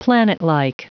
Prononciation du mot planetlike en anglais (fichier audio)
Prononciation du mot : planetlike